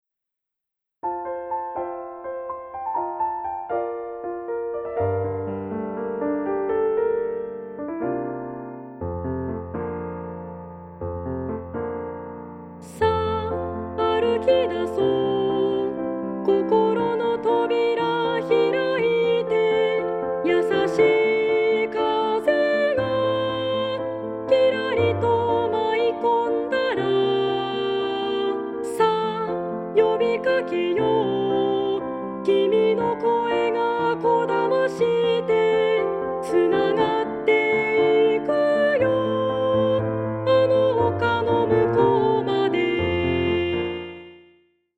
合唱パート練習CD
混声3部合唱／伴奏：ピアノ